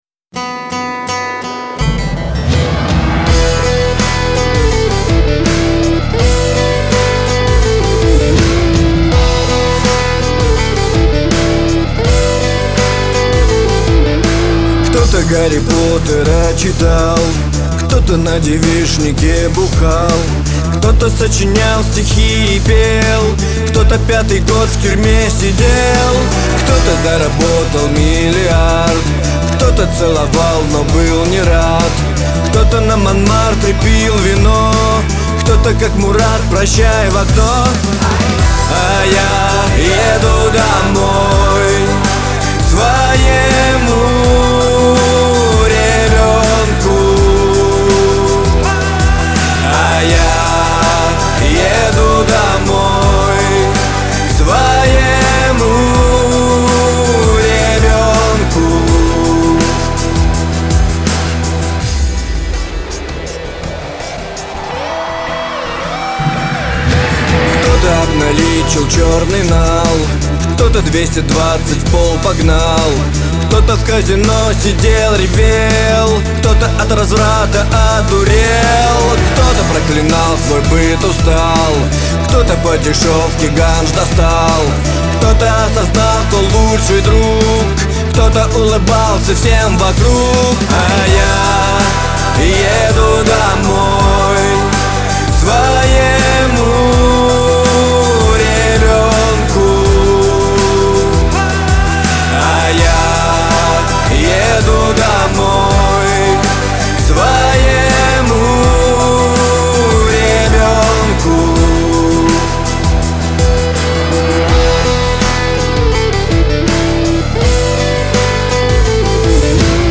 Стиль незнаю - современный, хипхоп, я незнаю точно